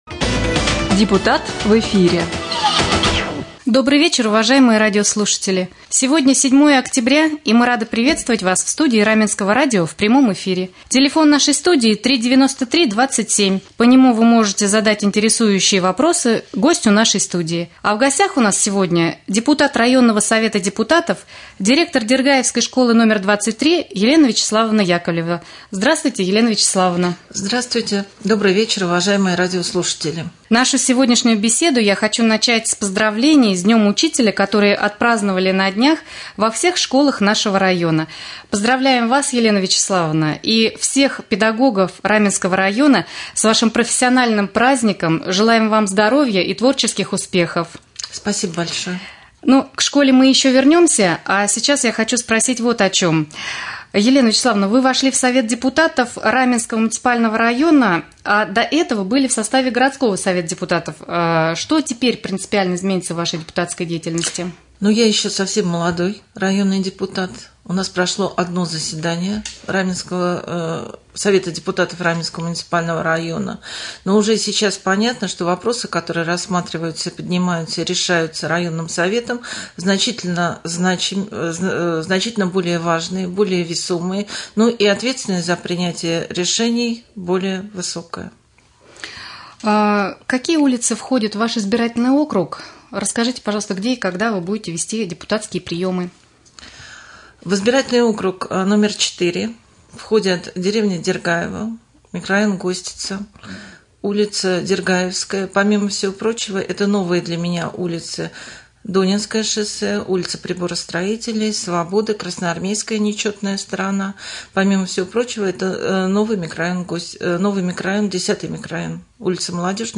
Прямой эфир с депутатом районного Совета депутатов